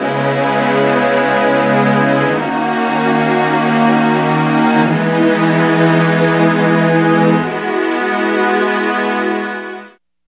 14 STR CHD-L.wav